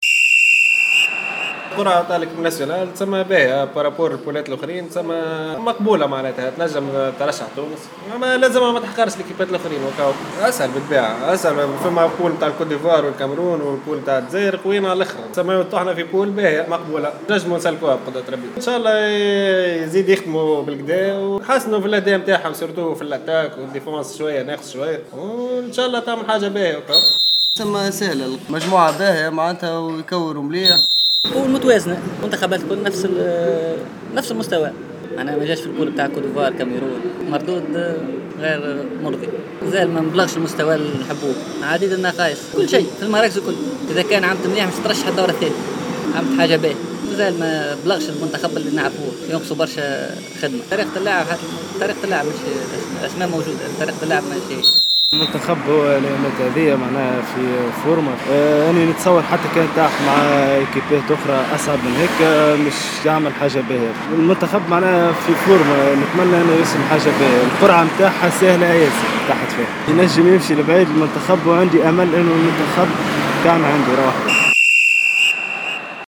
رأي الجمهور الرياضي في مجموعة المنتخب الوطني في بطولة كأس الأمم الأفريقية لكرة القدم